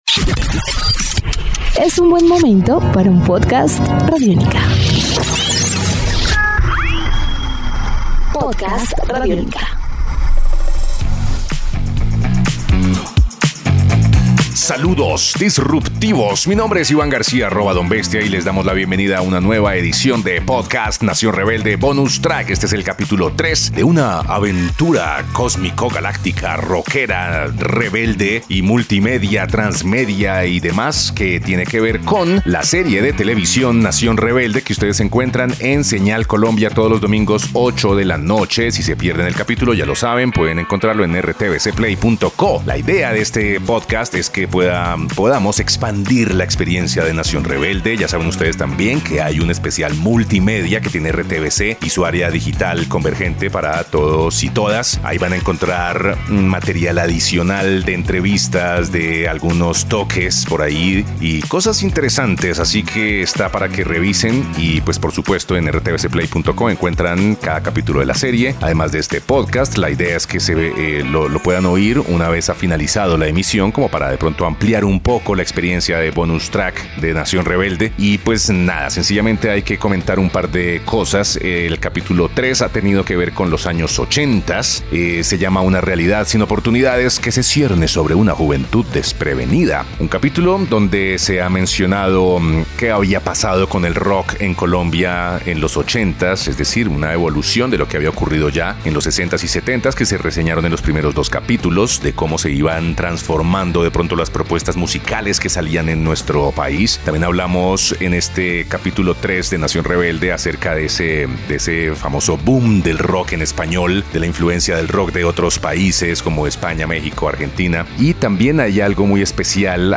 Sinopsis Un recorrido por los sonidos del rock colombiano de los 80